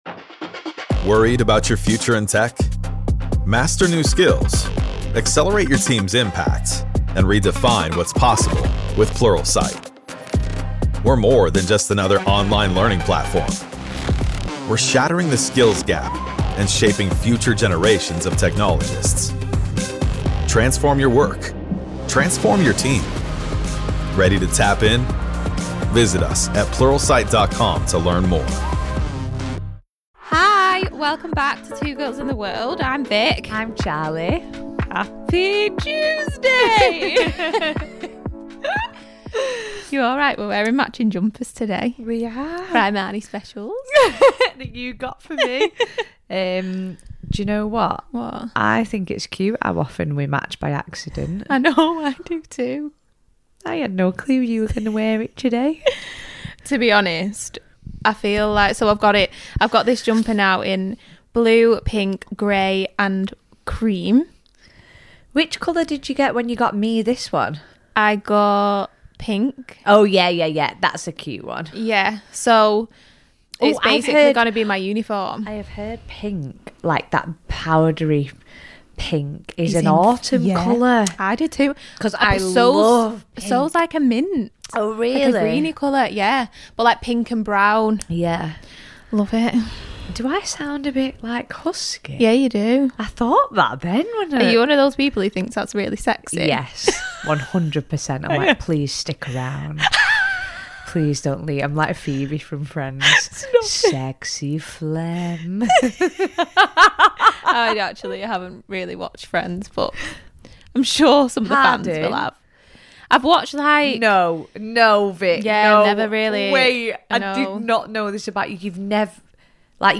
The girls are back for another episode